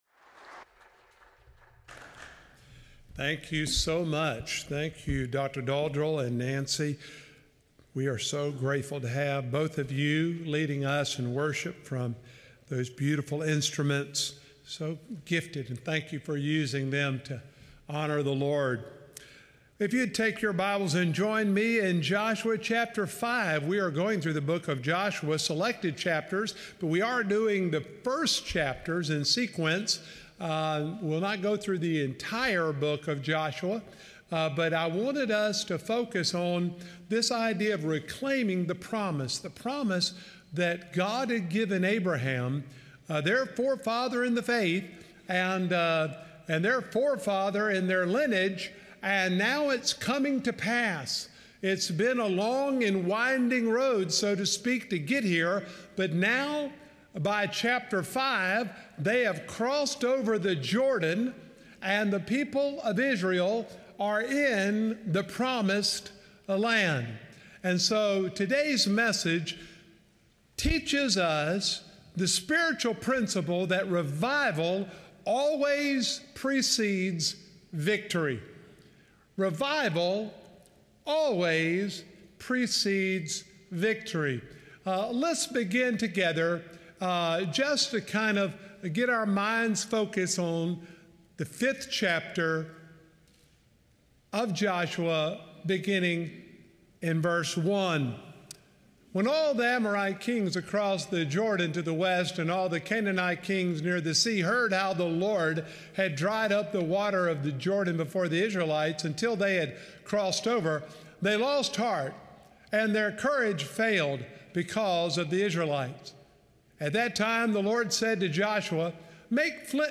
Sermons
November-2-2025-Sermon-Audio.m4a